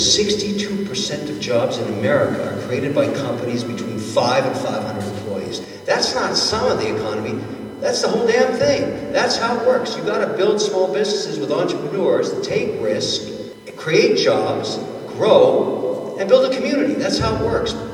Kevin O’Leary of “Shark Tank” was at the groundbreaking in Fairmont and said it is smaller businesses like Prime 6 that drive the country’s economy…